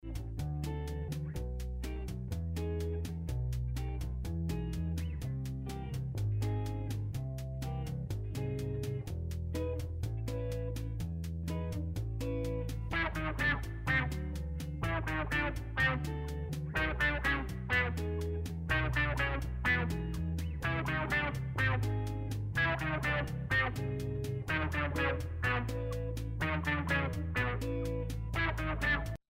schlagzeug
bass
akkordeon
keyboards
gitarre